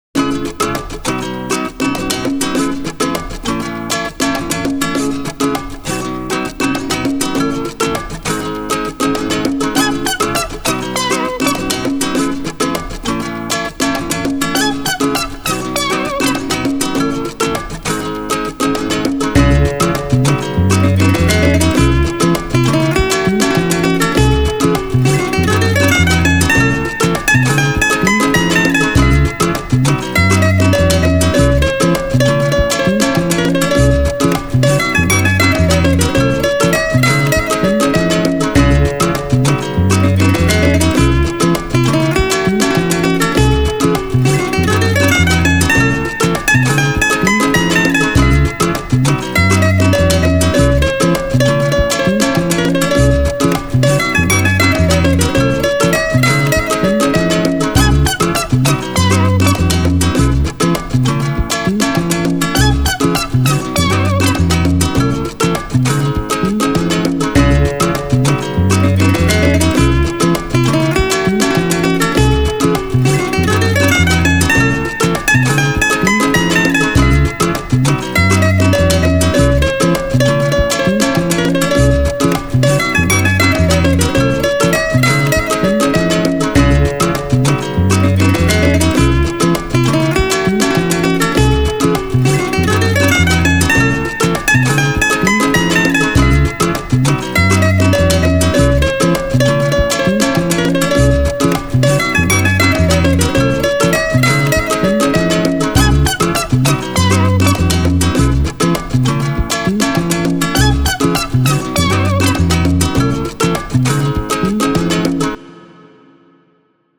エスニック
ギター
シンセ
ベース
打楽器
クール